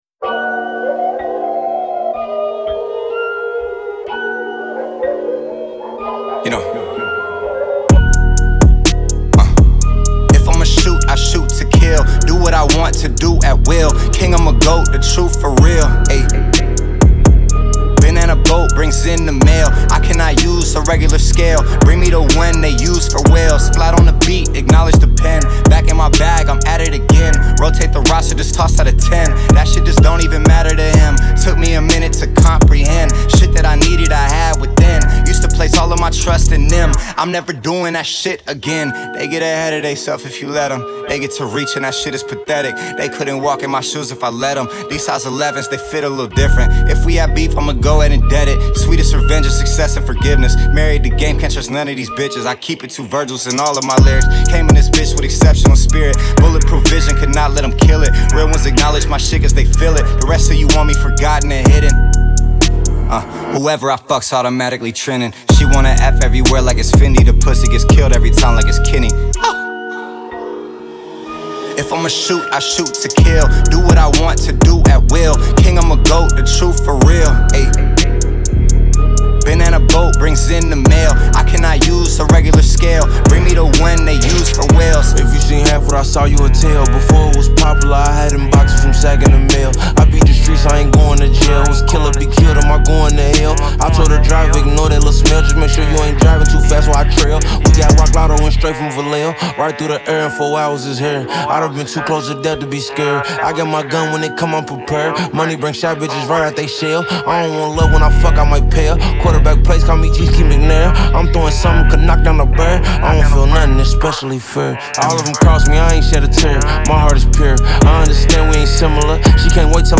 хип-хоп трек с энергичным и уверенным настроением